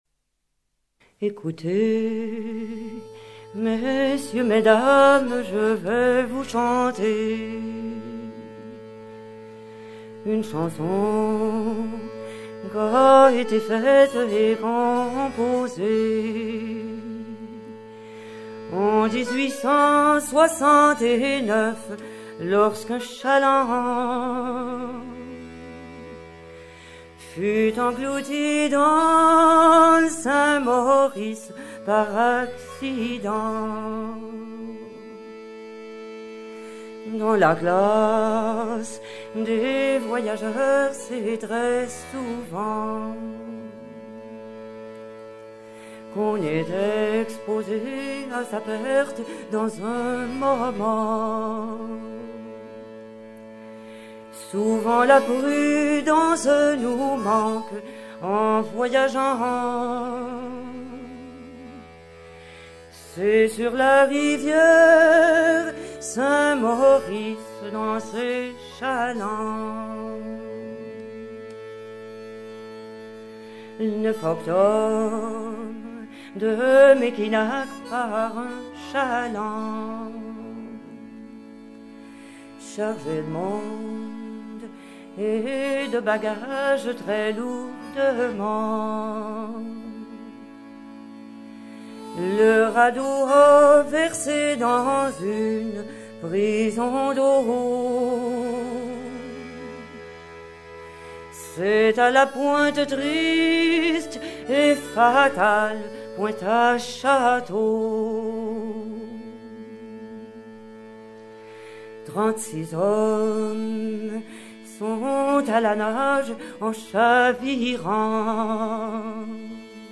complainte
Pièce musicale éditée